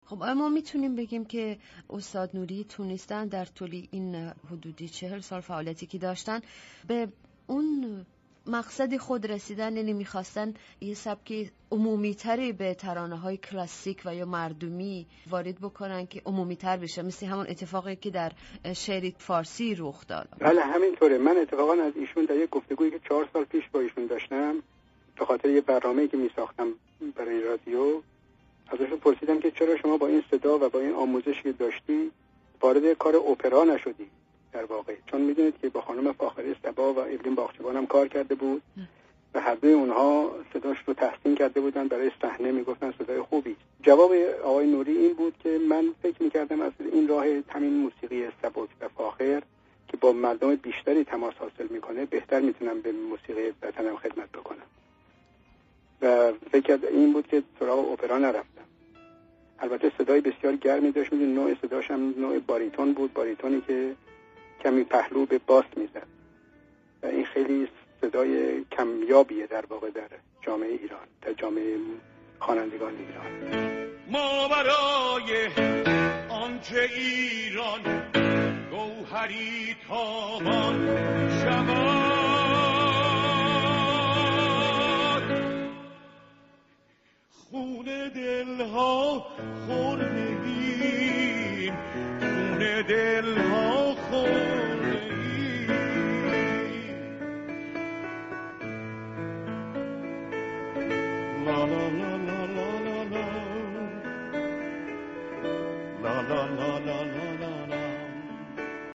Radio Zamaneh Interview
The following words have been removed from the sentences in Part 7 and replaced with  a pluck: